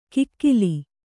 ♪ kikkili